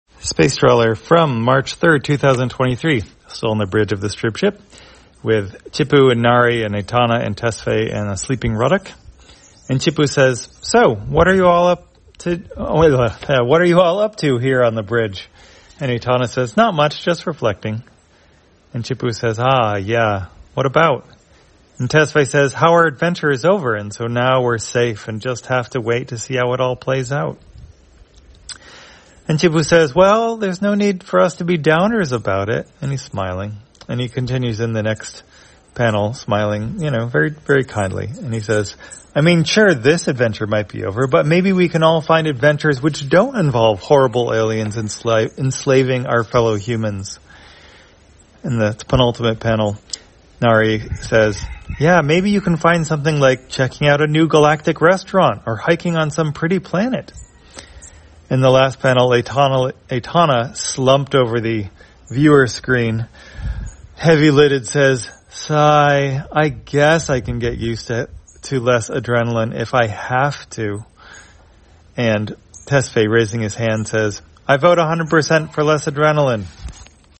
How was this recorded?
Spacetrawler, audio version For the blind or visually impaired, March 3, 2023.